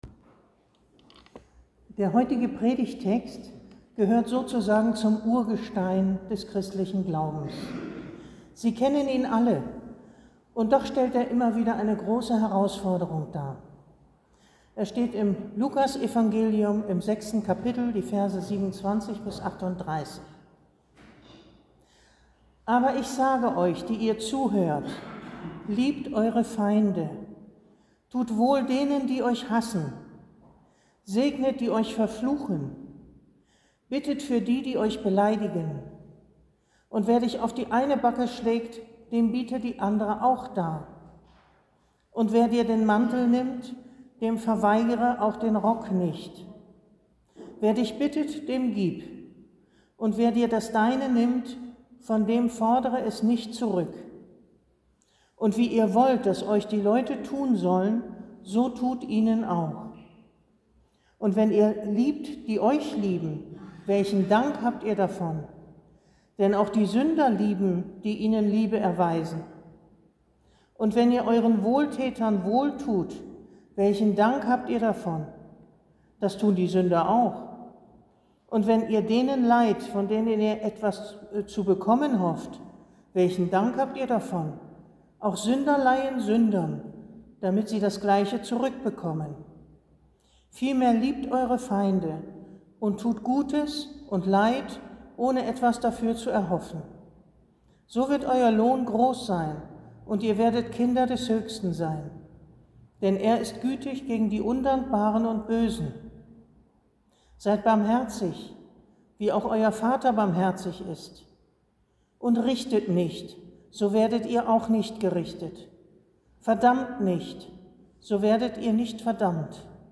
Predigt zum drittletzten Sonntag des Kirchenjahres